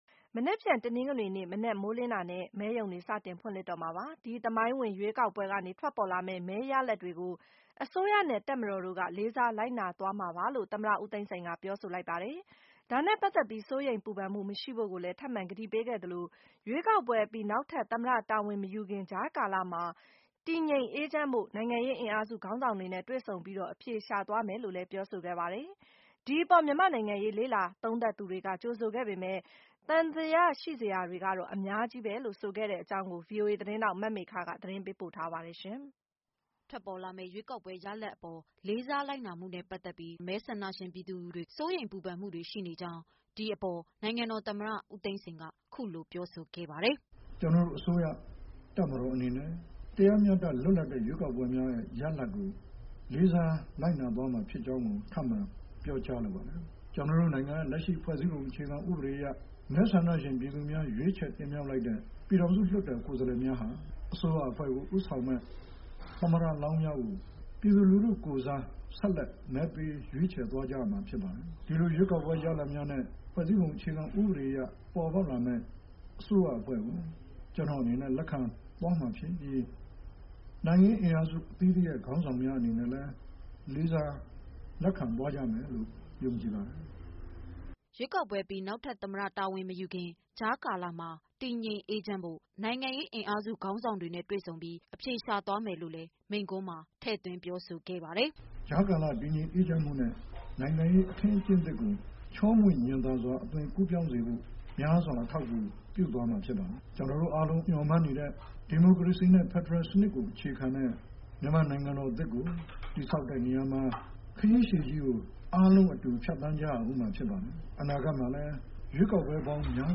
ဦးသိန်းစိန်မိန့်ခွန်း